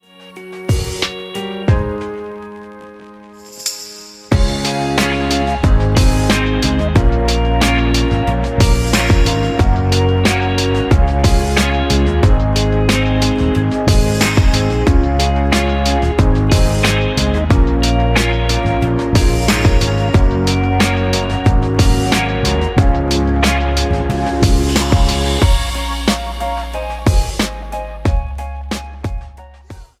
Backing track files: All (9793)